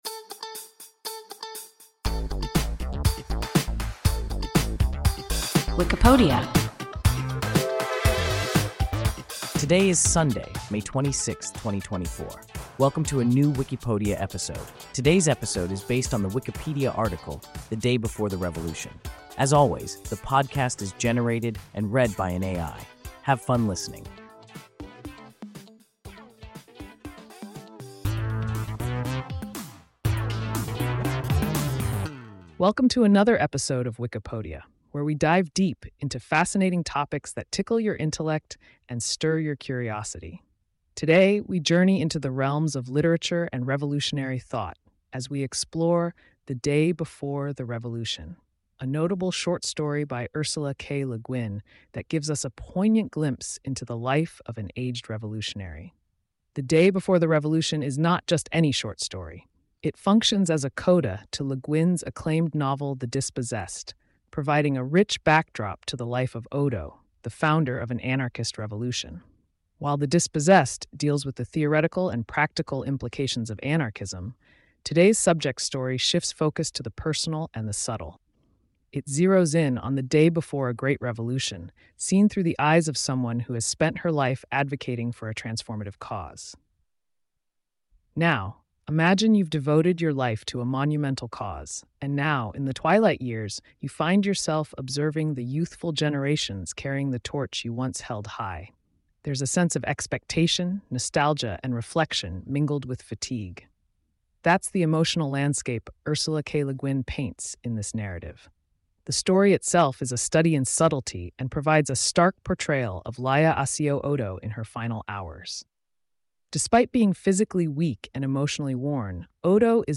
The Day Before the Revolution – WIKIPODIA – ein KI Podcast